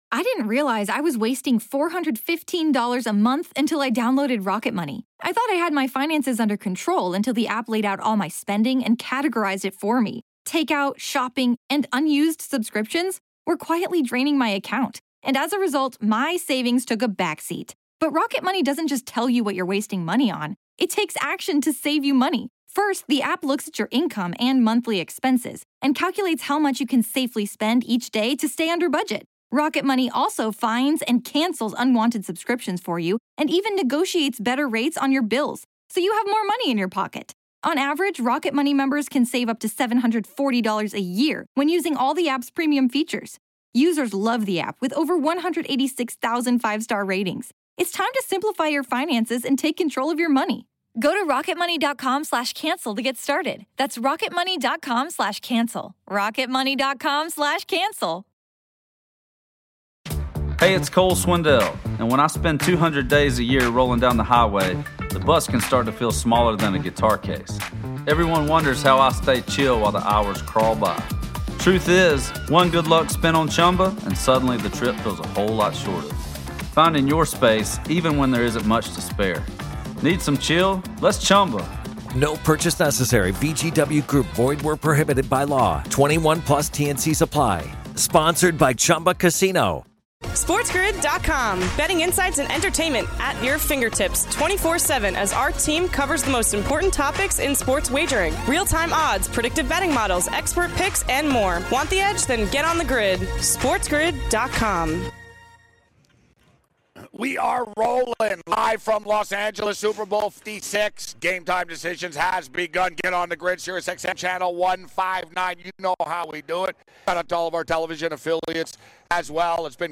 Live from LA for another Tuesday edition of Game Time Decisions!